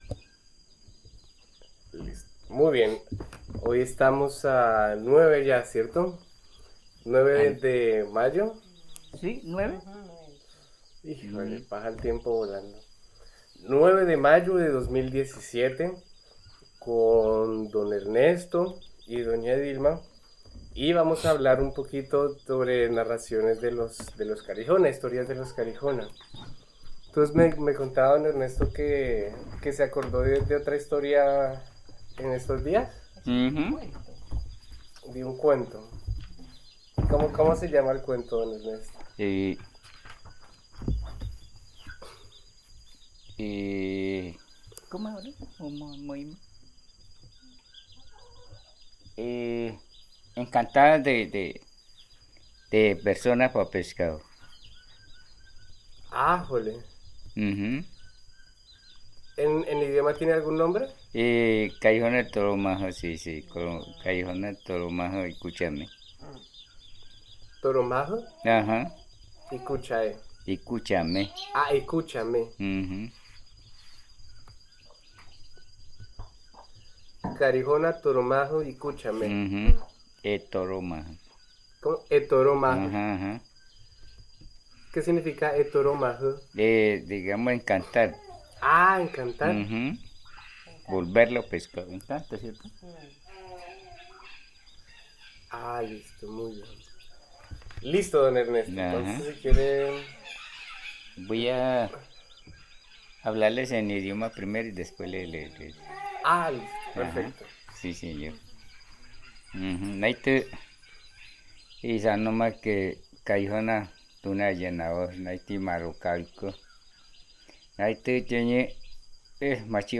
Narraciones. Güío.